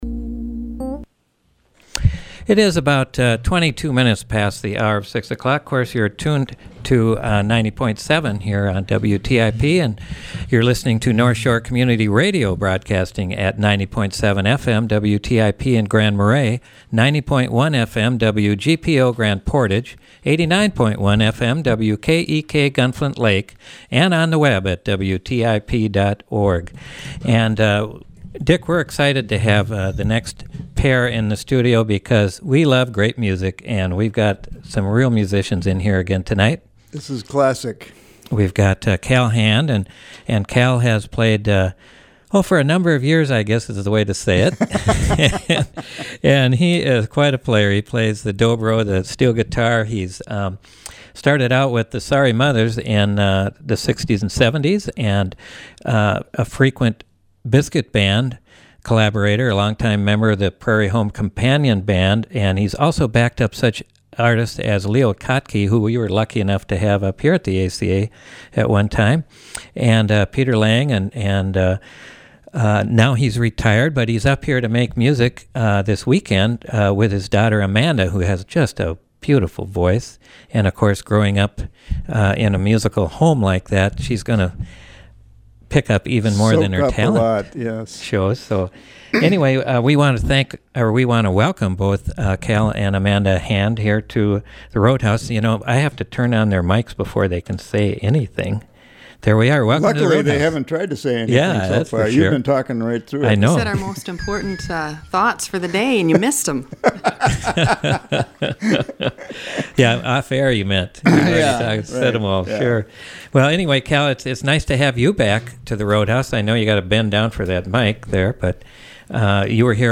steel guitar and dobro
singer-songwriter
They chatted and played some gorgeous music.
Live Music Archive